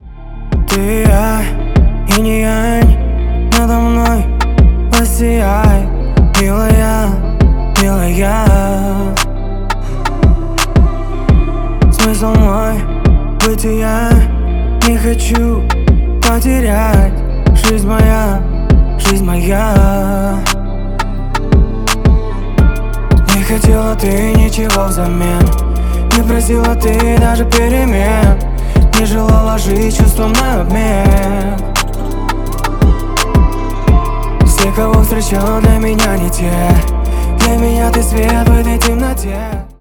• Качество: 320 kbps, Stereo
Поп Музыка
спокойные